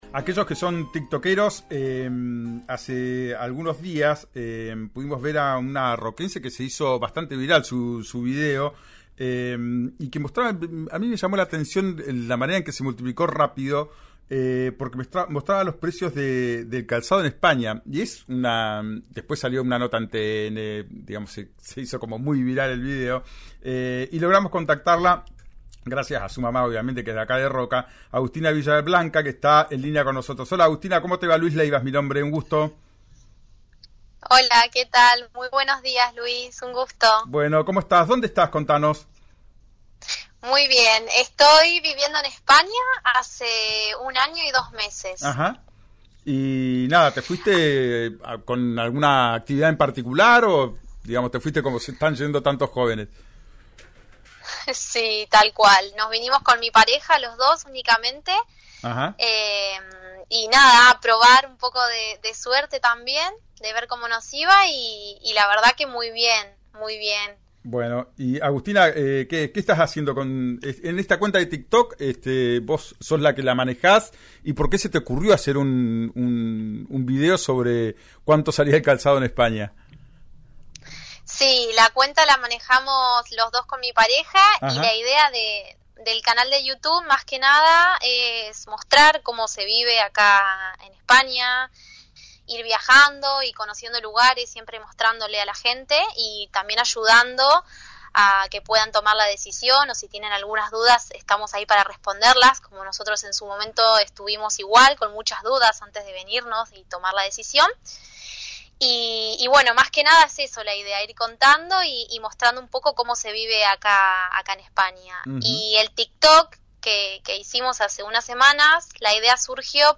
La influencer habló este martes en el programa «Ya es tiempo», de RÍO NEGRO RADIO, y contó que la iniciativa surgió porque «hace un tiempo vimos una noticia de un medio de Argentina, donde nos sorprendió mucho porque comprar un calzado deportivo allá era igual o más caro que pagar un alquiler«.